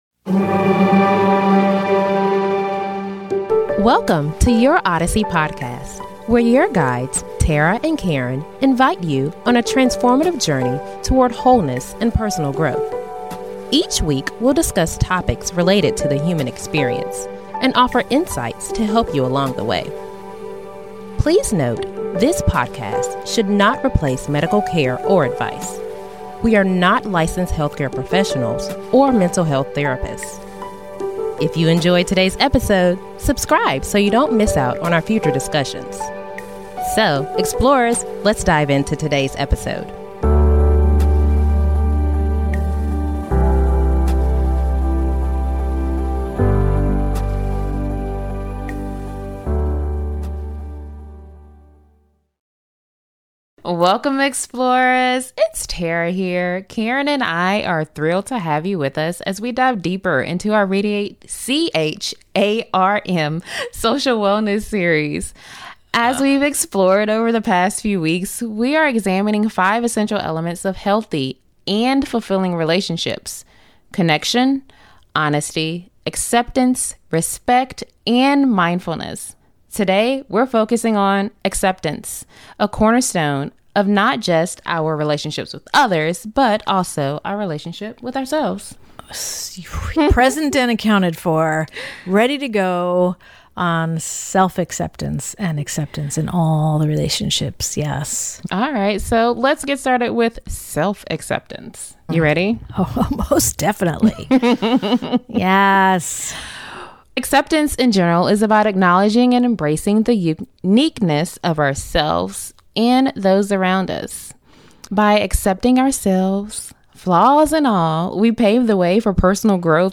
We include practical exercises and a guided meditation to help foster a greater sense of acceptance.